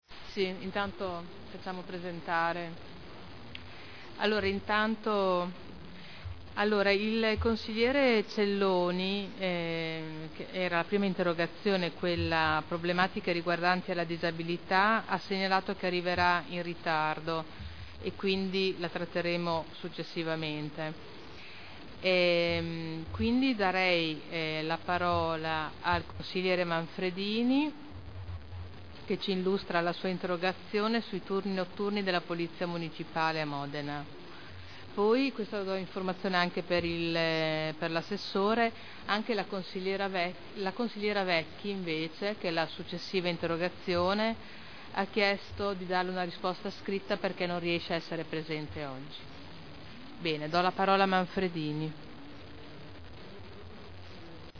Presidente - apertura di seduta — Sito Audio Consiglio Comunale